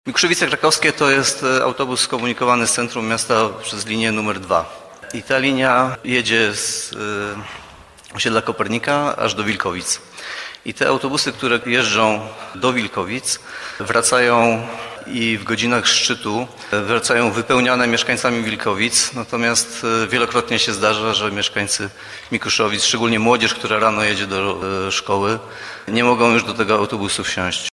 – W porozumieniu z wójtem gminy Wilkowice, zdecydowaliśmy się na to, żeby zwiększyć liczbę kursów do Wilkowic Górnych, z 38 do 44 […]. Liczymy na to, że ten problem [z przepełnionymi autobusami – red.] przestanie mieć miejsce – mówił w trakcie ostatniej sesji miejskiej Przemysław Kamiński, zastępca prezydenta Bielska-Białej.